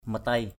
/mə-teɪ/ 1. (d.) ngọc, đá quý = perle précieuse = precious pearl. matei mariah mt] mr`H thạch anh = jaspe = jasper. matei batuw mt] bt~w ngọc bích = jade...